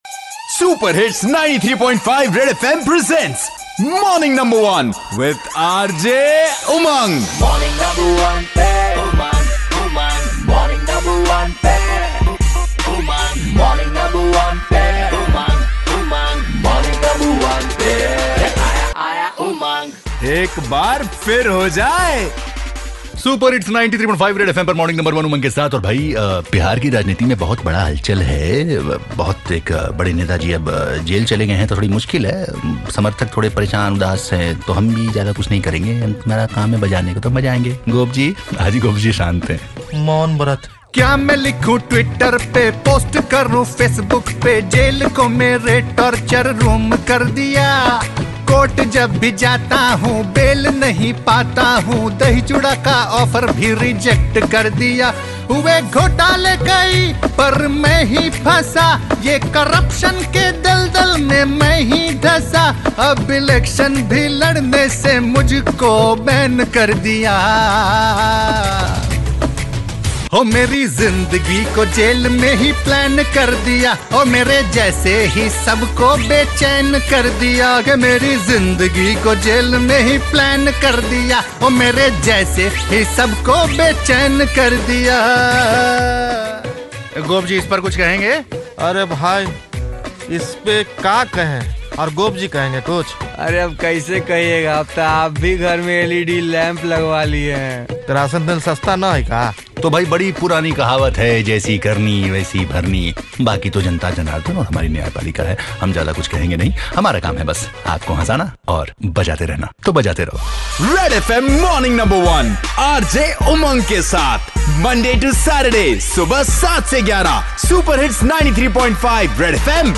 kuch ga kar sunayenge